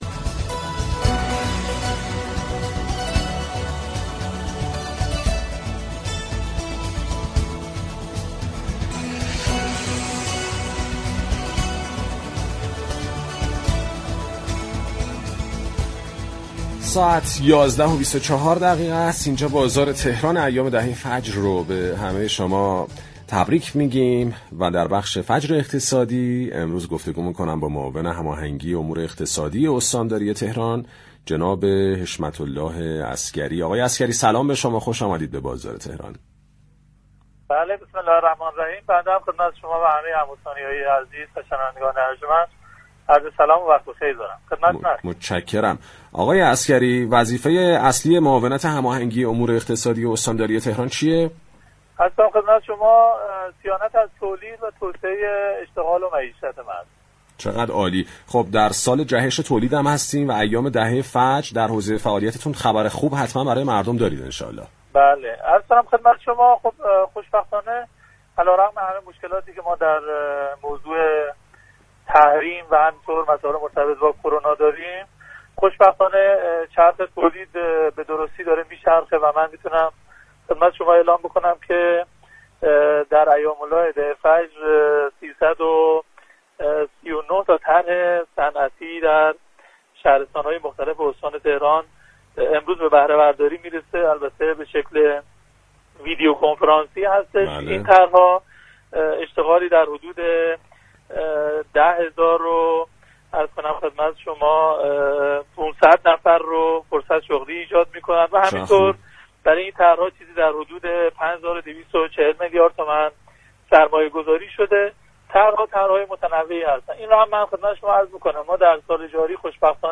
حشمت الله عسگری در گفتگو با برنامه بازار تهران